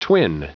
Prononciation du mot twin en anglais (fichier audio)
Prononciation du mot : twin